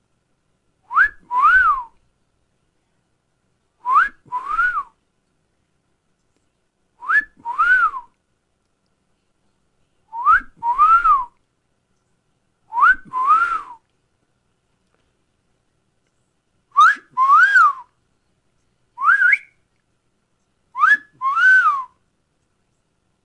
猫叫声口哨
描述：猫呼叫/狼口哨的各种各样的需要。在演播室。